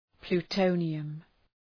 {plu:’təʋnıəm}
plutonium.mp3